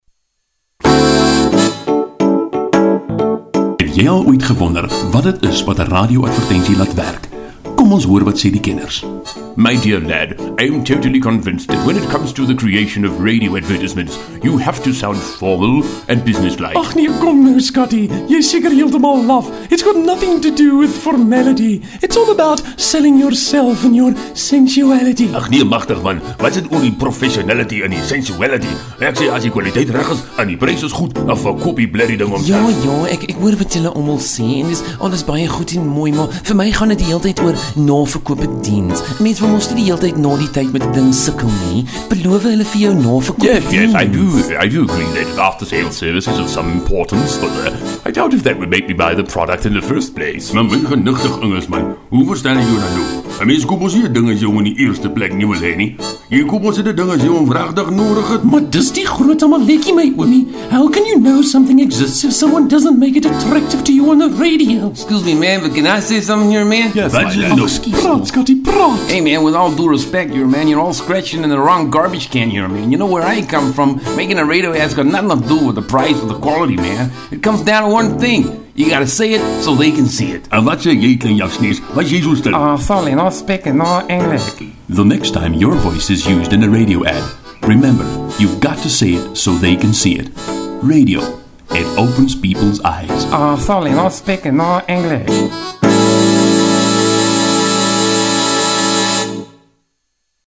Radio Ads
Multi Character
Multi-Charactor.mp3